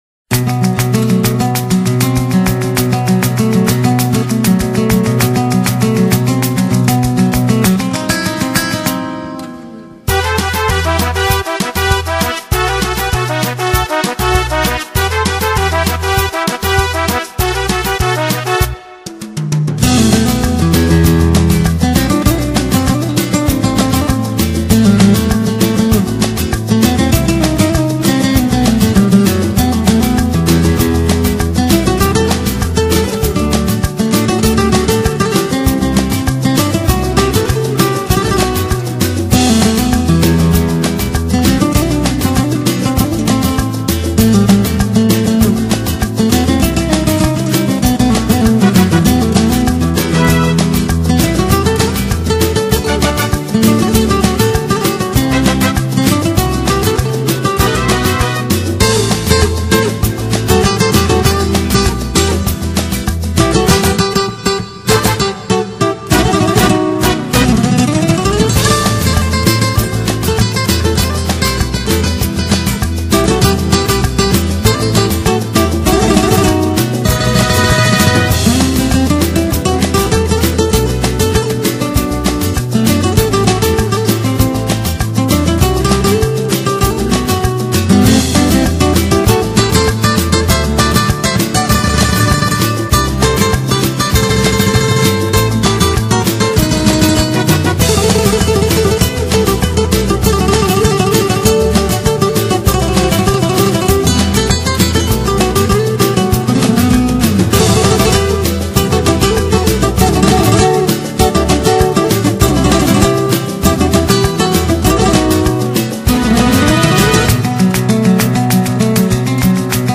第一首以轻 快爽朗的主題曲